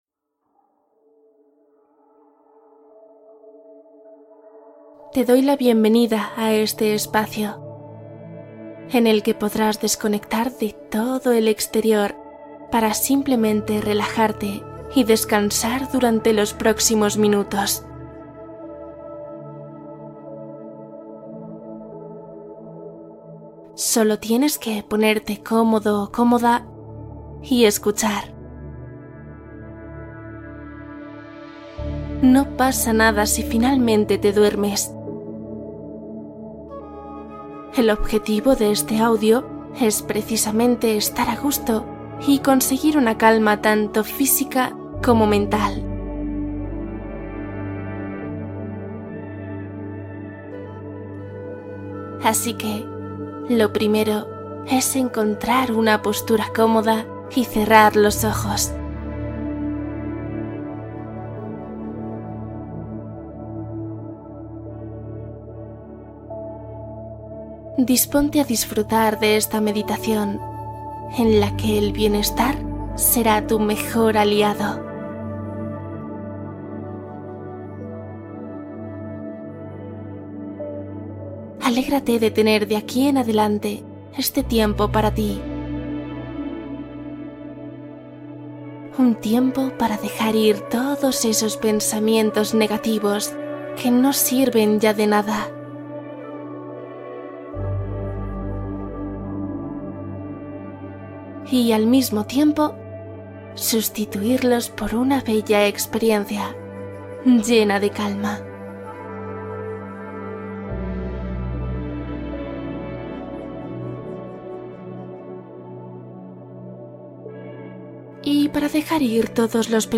Sana tus emociones mientras duermes | Visualización guiada